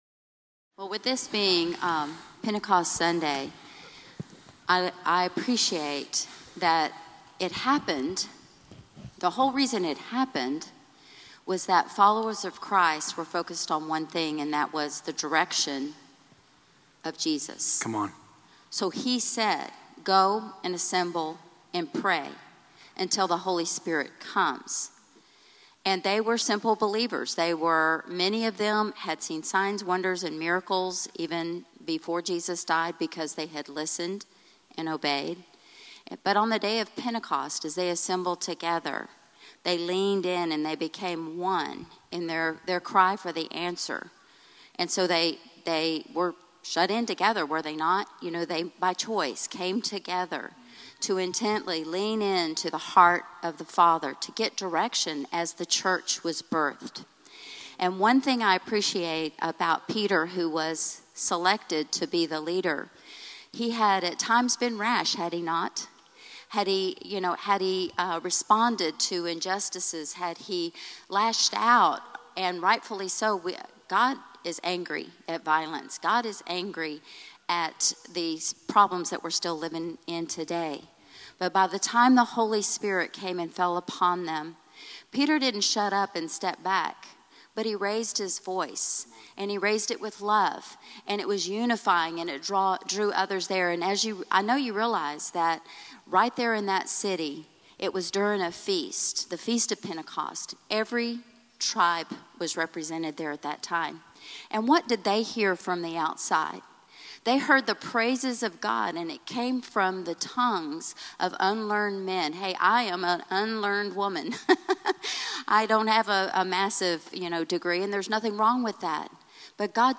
A Conversation
CL Panel